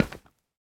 Minecraft Version Minecraft Version latest Latest Release | Latest Snapshot latest / assets / minecraft / sounds / block / bone_block / step1.ogg Compare With Compare With Latest Release | Latest Snapshot